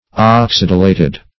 oxidulated - definition of oxidulated - synonyms, pronunciation, spelling from Free Dictionary
Search Result for " oxidulated" : The Collaborative International Dictionary of English v.0.48: Oxidulated \Ox*id"u*la`ted\, a. (Chem.)